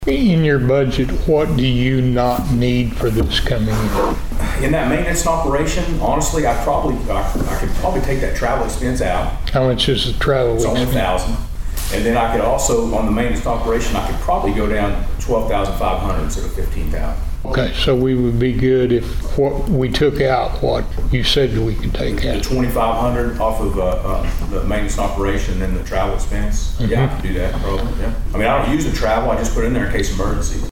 At Monday's Board of Osage County Commissioners meeting, there was discussion regarding the 2025-2026 fiscal year budgets for the assessor's office, election board and planning and zoning department.
Budget Talk Clip 2.mp3